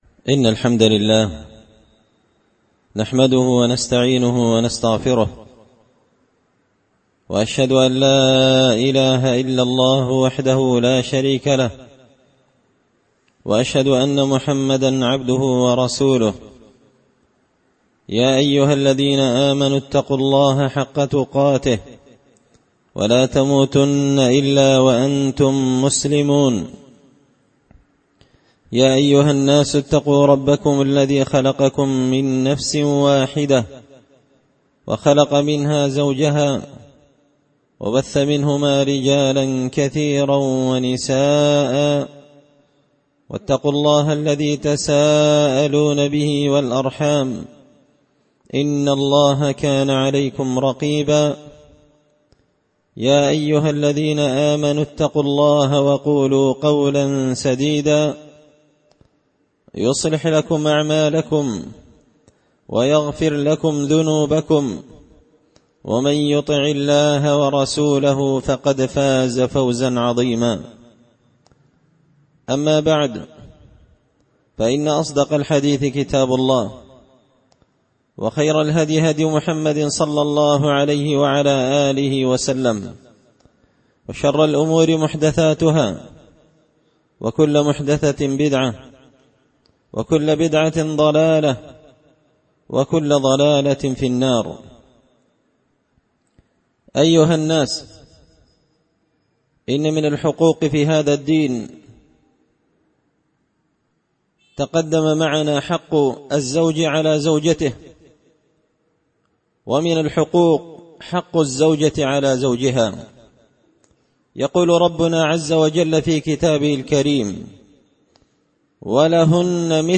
خطبة جمعة بعنوان حق الزوجة على زوجها
دار الحديث بمسجد الفرقان ـ قشن ـ المهرة ـ اليمن